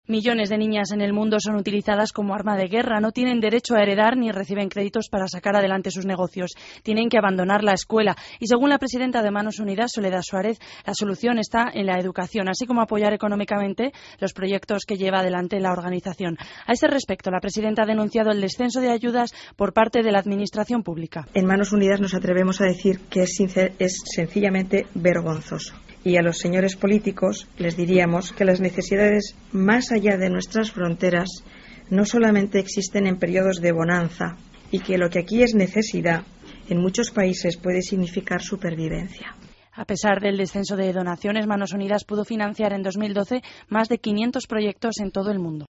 AUDIO: Crónica